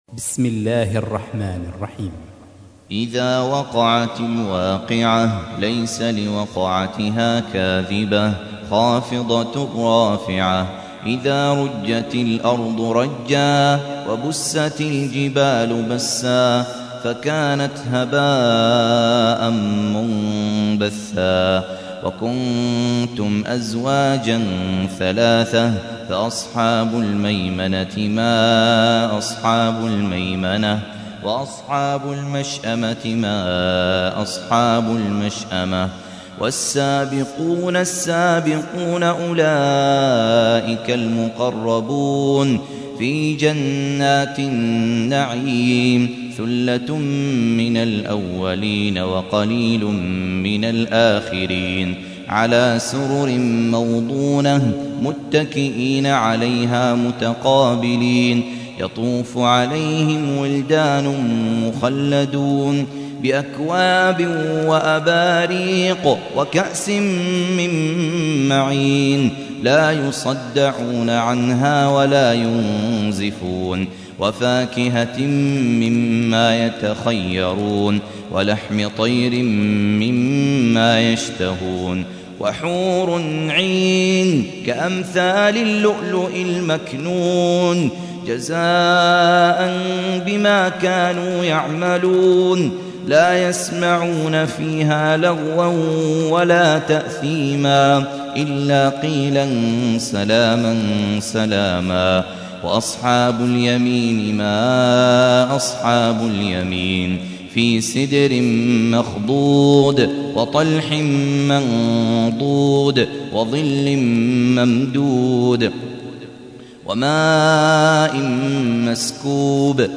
تحميل : 56. سورة الواقعة / القارئ خالد عبد الكافي / القرآن الكريم / موقع يا حسين